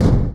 EXPLOSION_Subtle_Hollow_stereo.wav